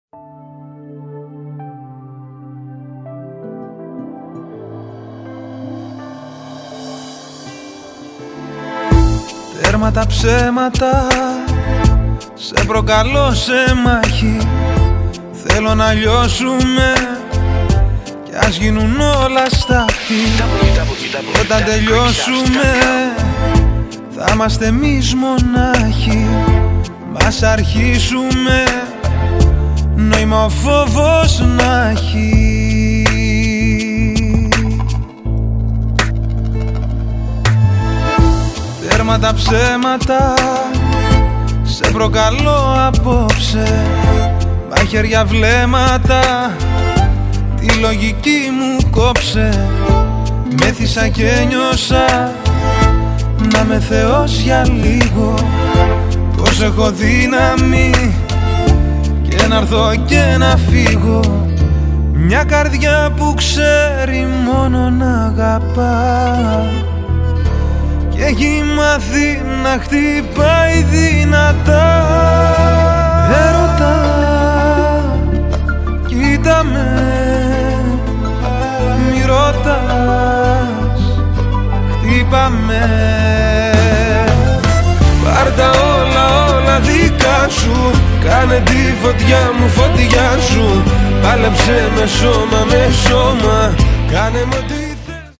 The limited edition album features 11 new modern pop tracks.